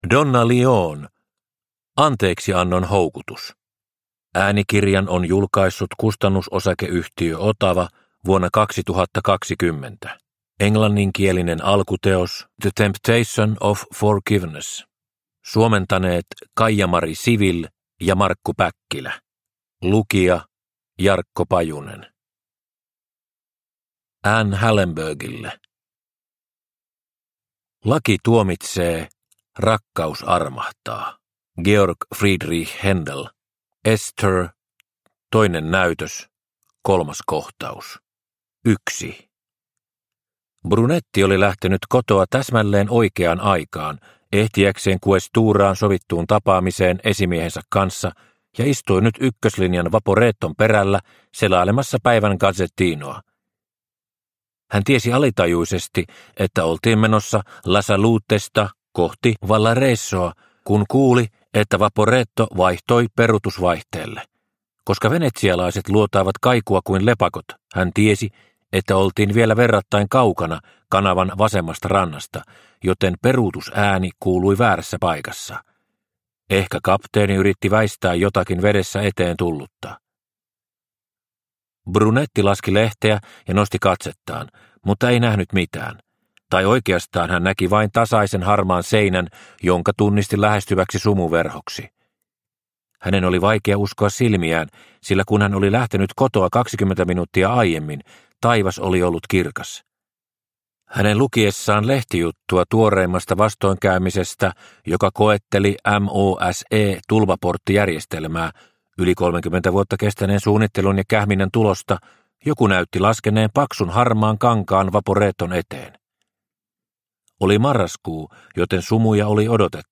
Anteeksiannon houkutus – Ljudbok – Laddas ner